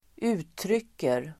Uttal: [²'u:tryk:er]